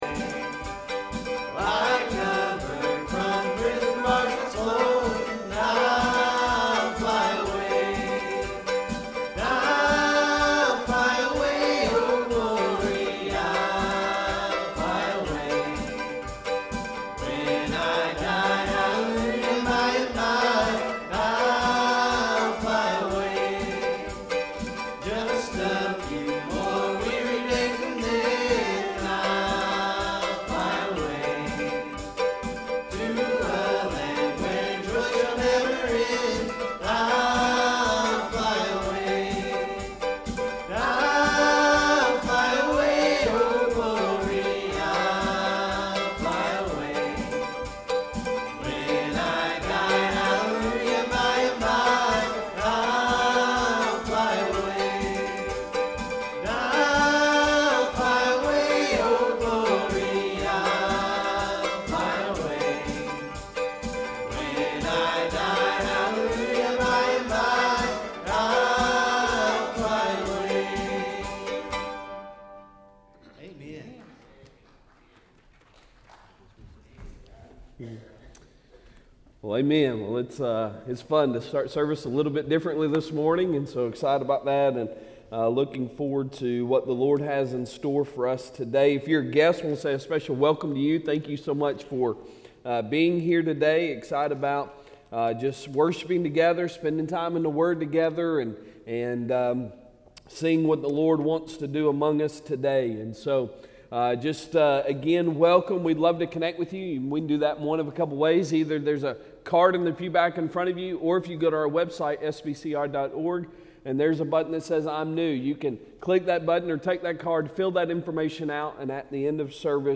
Sunday Sermon October 15, 2023